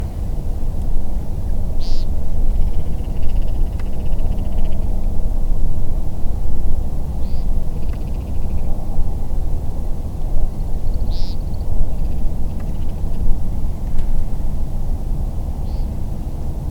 AMERICAN WOODCOCK
It is always fun to sit in the pre-dawn darkness at Oakwoods Metropark, Wayne County, and listen to the courtship activities of this species.